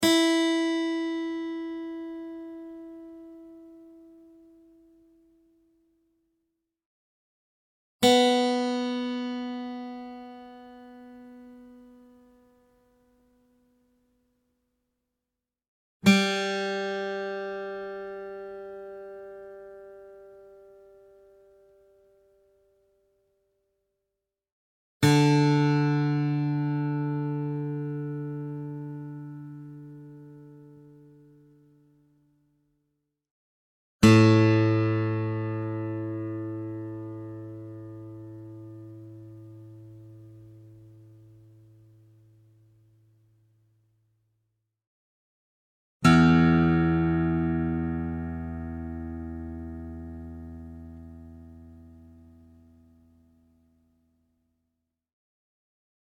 Real acoustic guitar sounds in Standard Tuning
Guitar Tuning Sounds